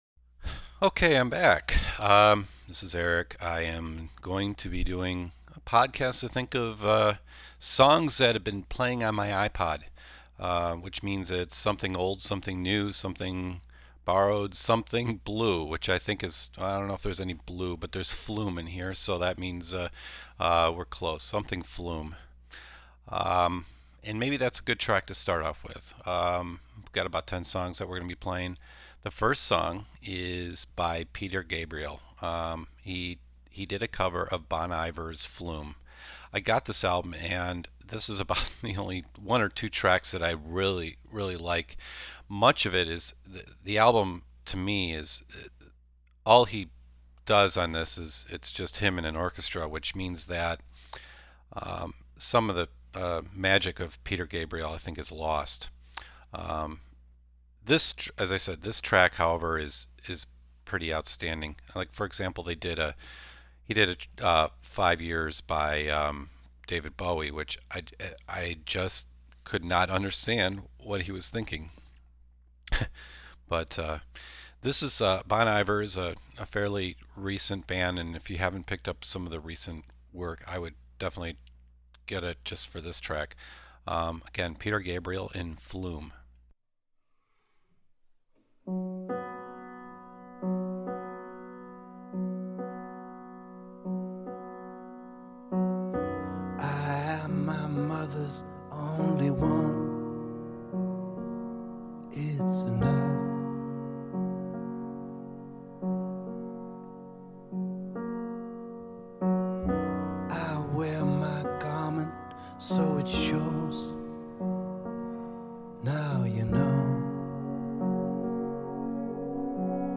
Every once in a while there is always a need to put my mouth behind the microphone and speak to my imaginary friends as to what I have been listening to.
The podcast is a mixture of young and old.